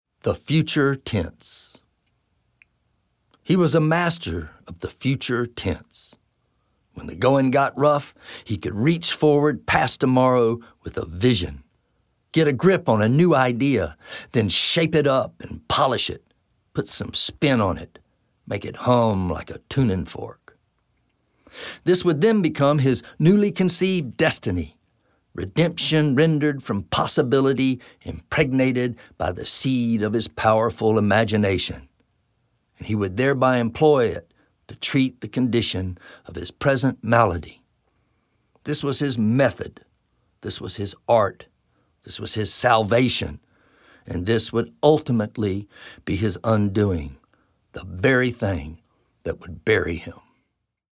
Spoken Poems